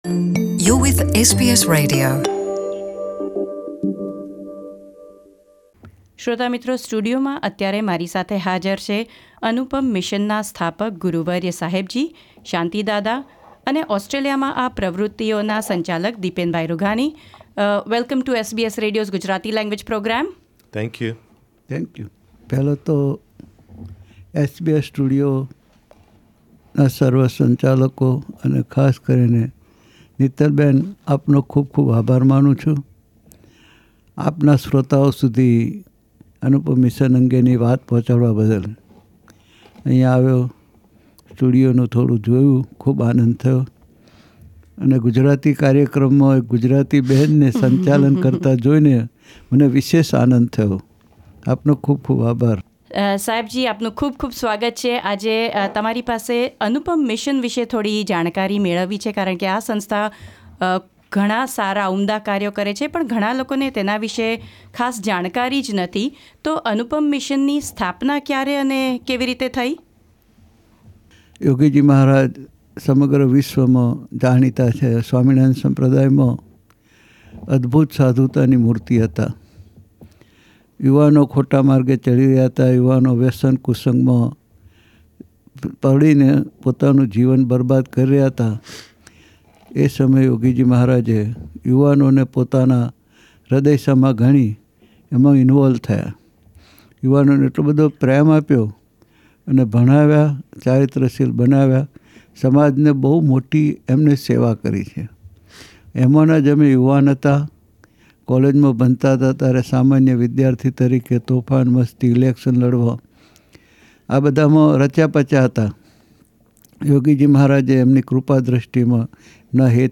વાર્તાલાપ.